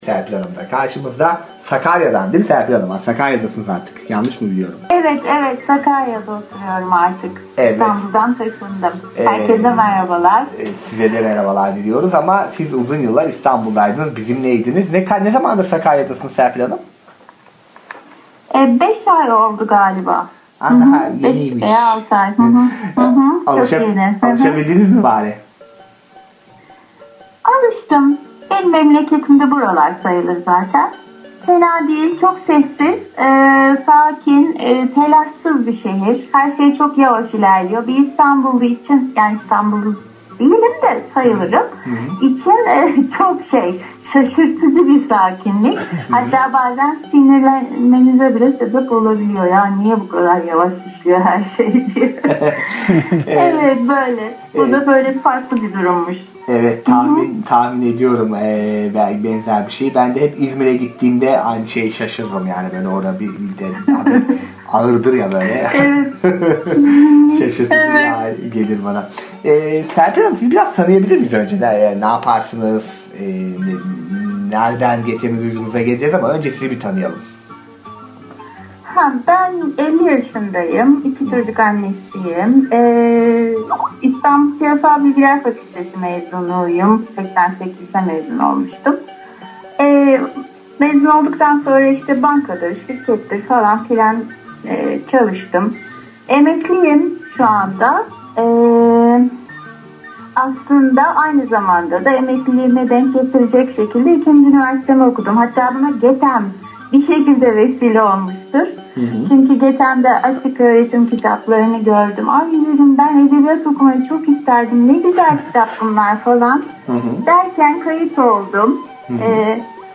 Gönüllü okuyucu röportajları